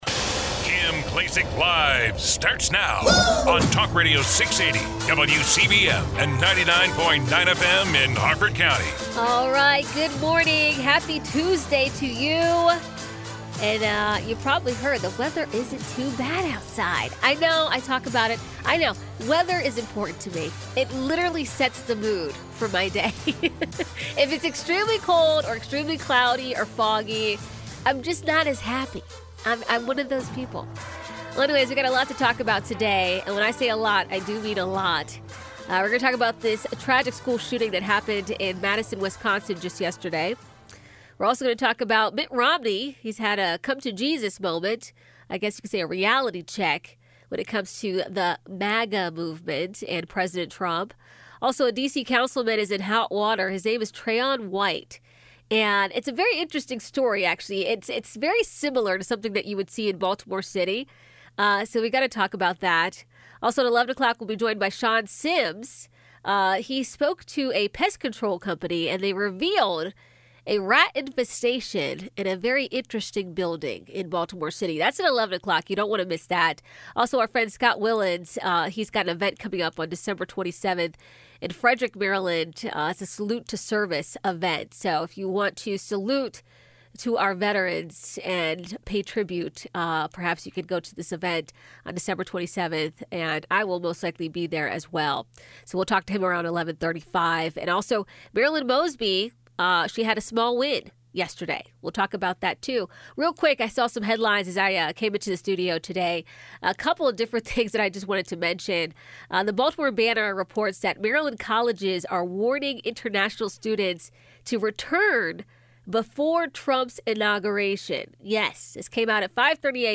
Kim Klacik is a dynamic voice who isn’t afraid to speak her mind.
Don’t miss your chance to hear from the one and only Kim Klacik live on WCBM weekdays from 9am to noon.